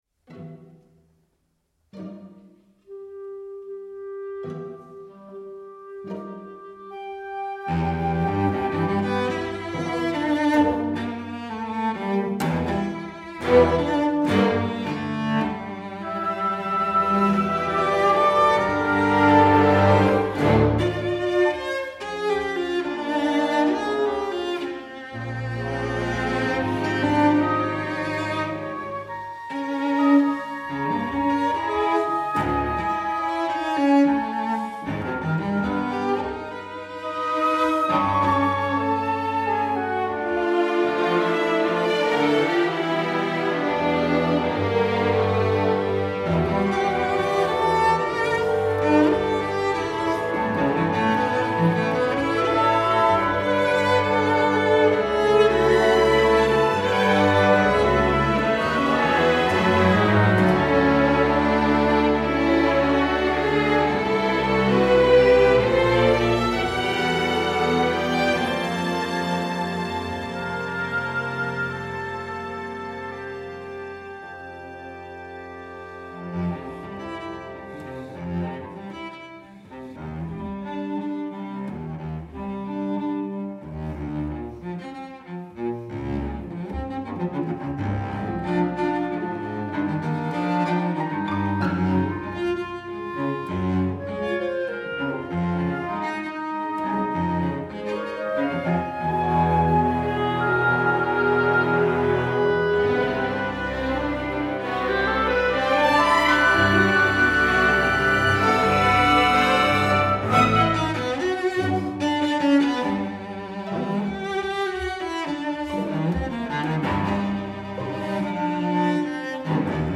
1011/1000/cello solo/stgs